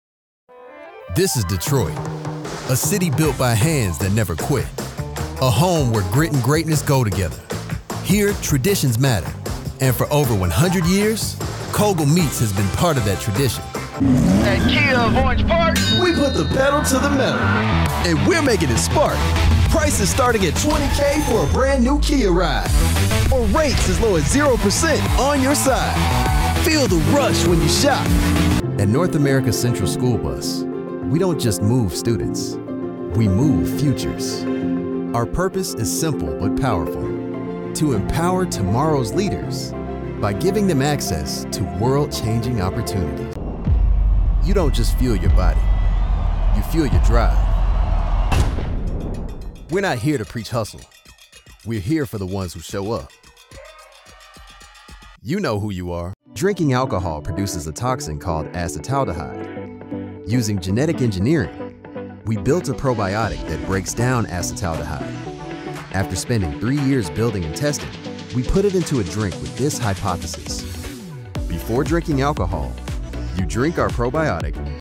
Commercial Demo Reel
Young Adult
Middle Aged
I record from a professional home studio and provide clean, broadcast-ready audio with fast turnaround, clear communication, and attention to detail from start to finish.